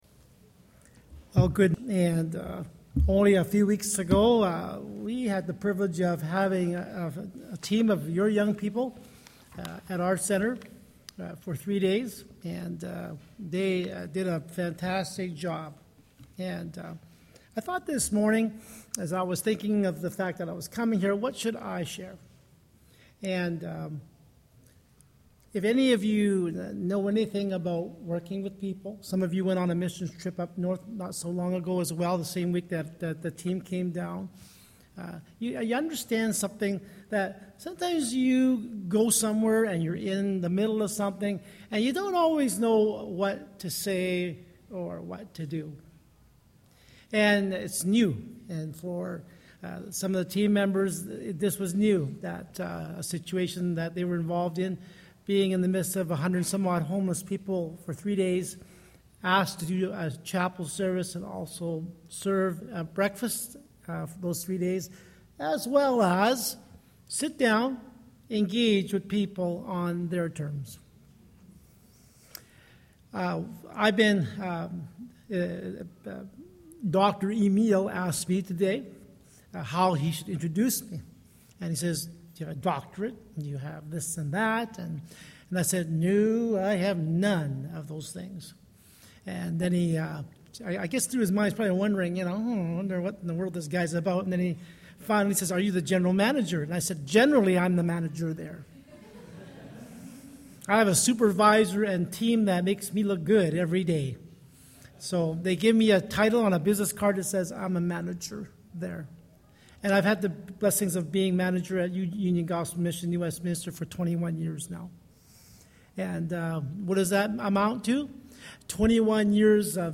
Sermons | Lord's Grace Church